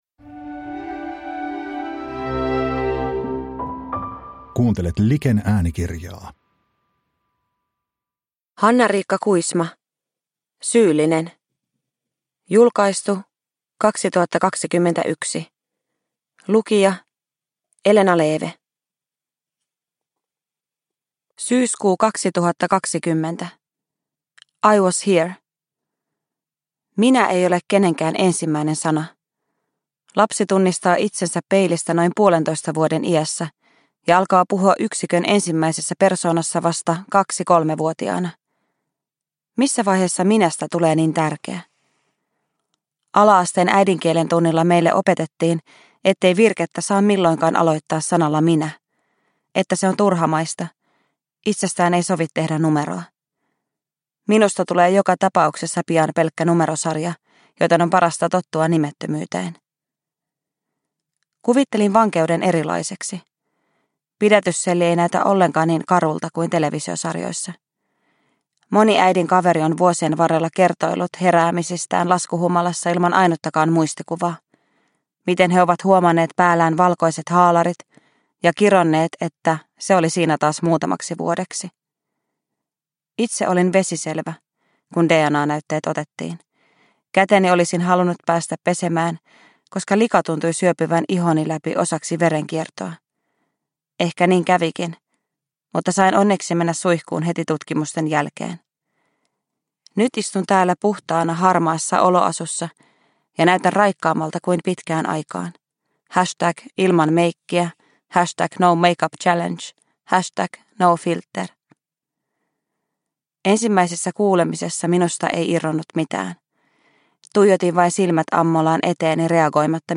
Syyllinen – Ljudbok – Laddas ner
Uppläsare: Elena Leeve